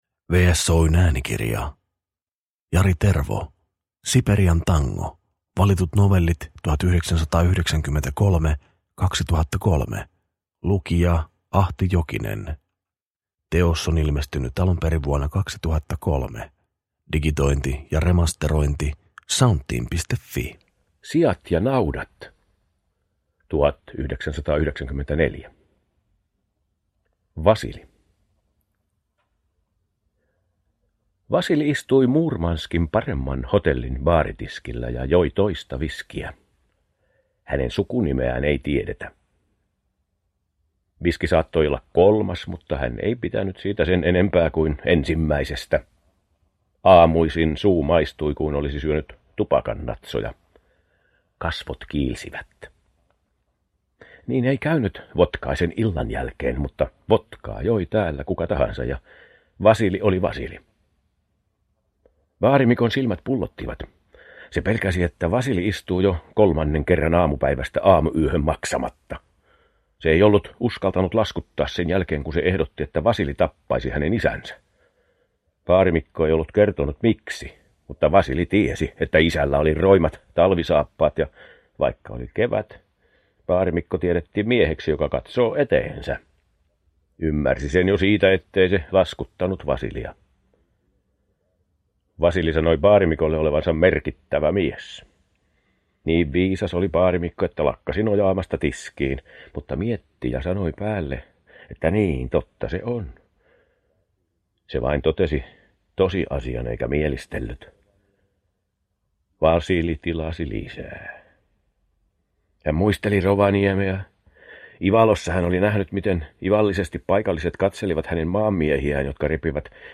Valitut novellit 1993-2003 – Ljudbok – Laddas ner